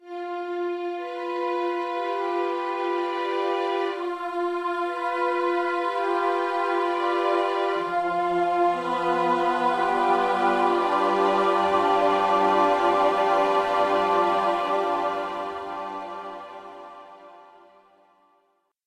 Here was the first version of the death scene before seeing rehearsals: